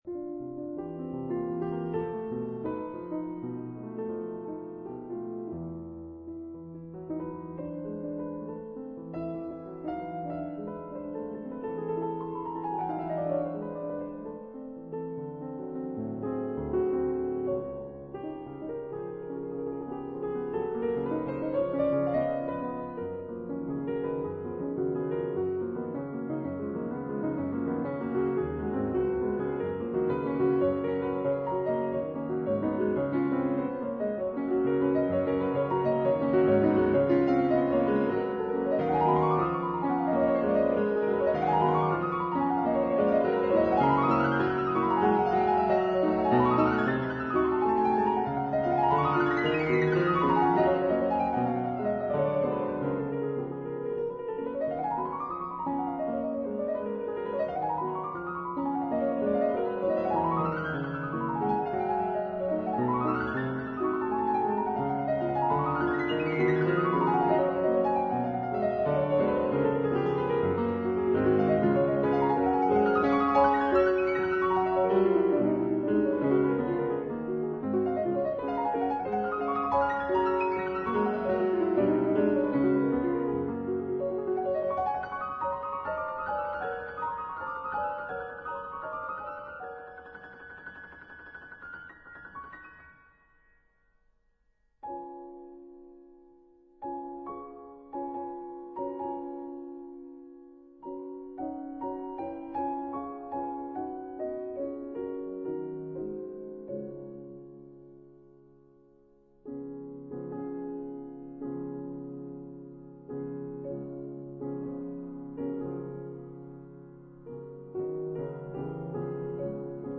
即興曲
特別是左右手的獨立性，更是絕佳。